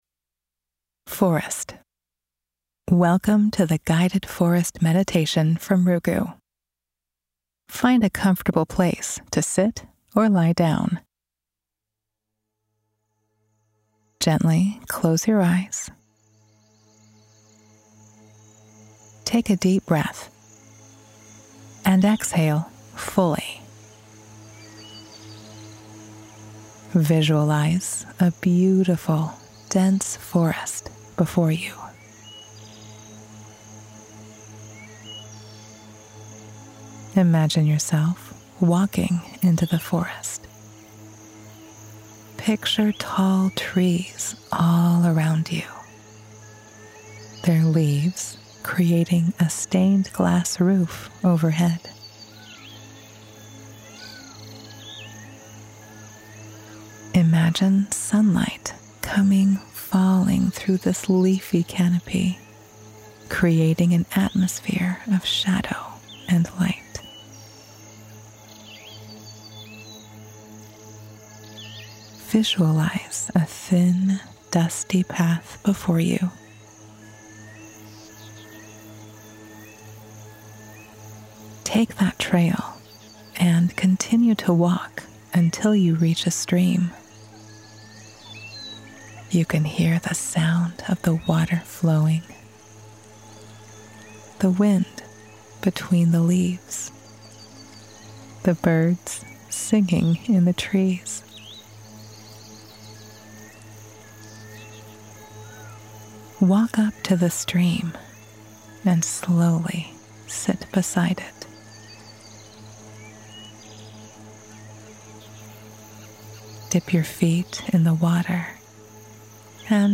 Meditate – Guided Imagery Forest